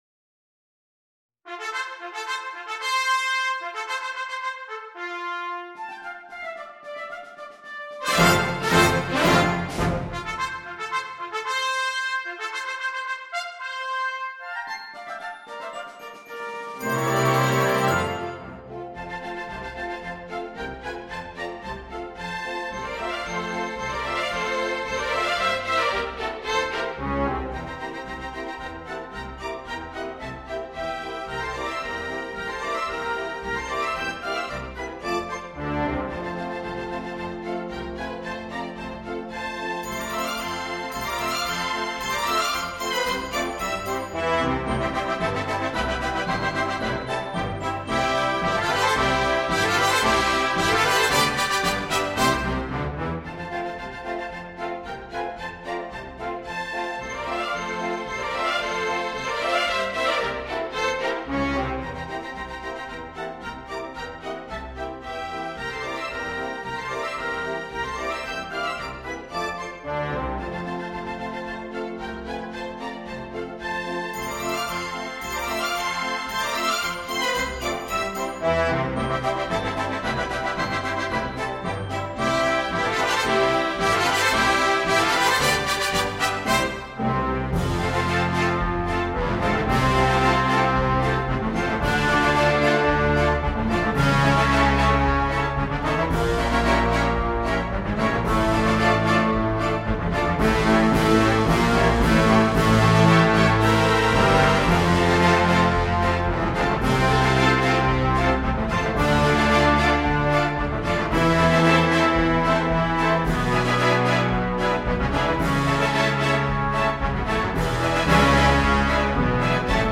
sans instrument solo
marches
Wood Wind 2 / 2 / 2 / 2
Brass 4 / 3 / 3 / 1
Timpani / Percussion / Harp
Strings 10 / 8 / 6 / 4 / 4